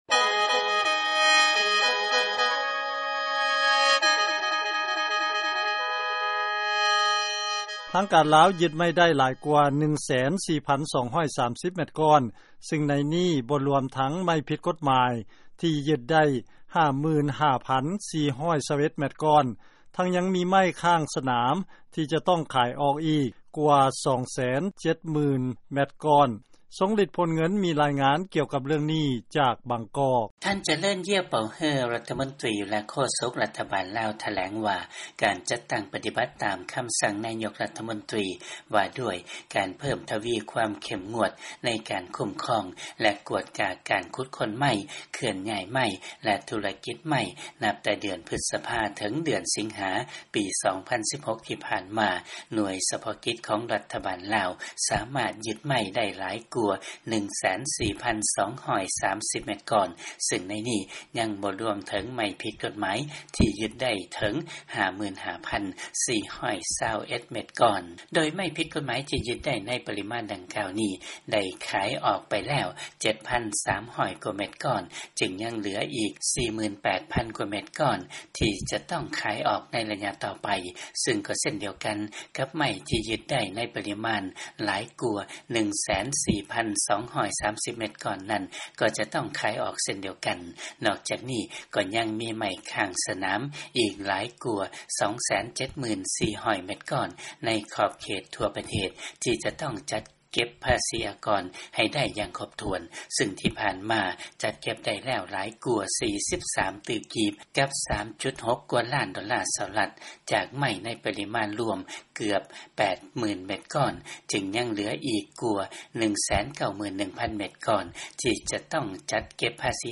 ເຊີນຟັງ ລາຍງານ ທາງການລາວຢຶດໄມ້ໄດ້ ຫຼາຍກວ່າ 104,230 ແມັດກ້ອນ ບໍ່ລວມທັງໄມ້ຜິດກົດໝາຍ ຕື່ມອີກ